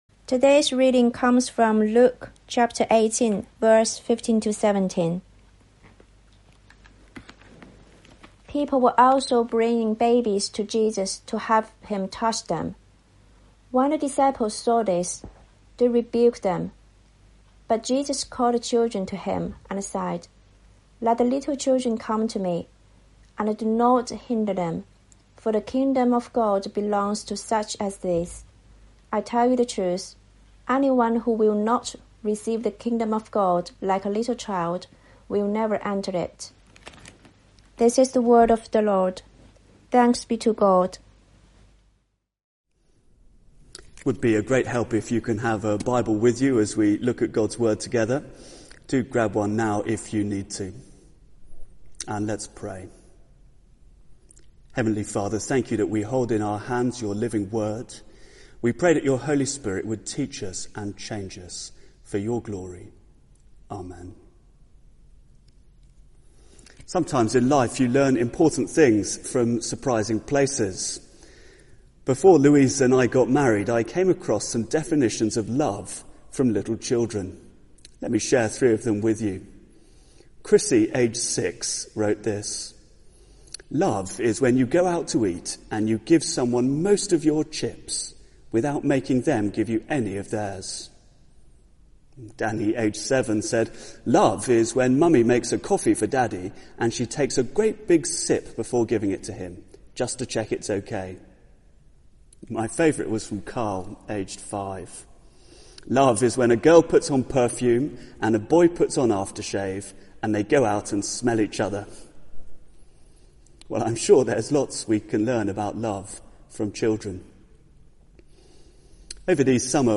Theme: ...little children Online Service Talk (Audio) Search the media library There are recordings here going back several years.